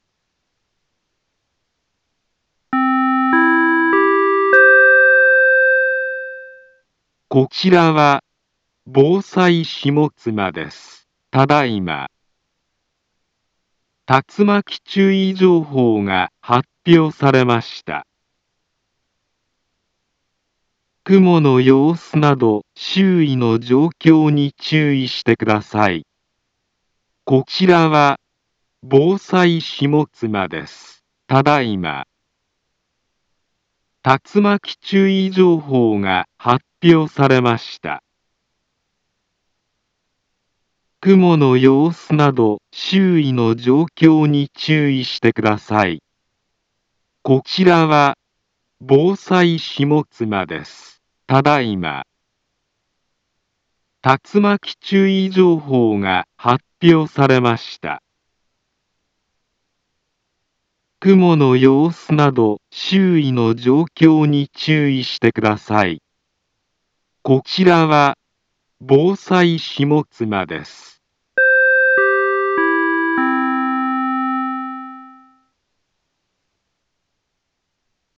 Back Home Ｊアラート情報 音声放送 再生 災害情報 カテゴリ：J-ALERT 登録日時：2025-08-06 17:58:34 インフォメーション：茨城県北部、南部は、竜巻などの激しい突風が発生しやすい気象状況になっています。